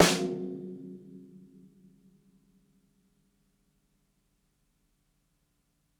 ROOMY_SNARE_HARD.wav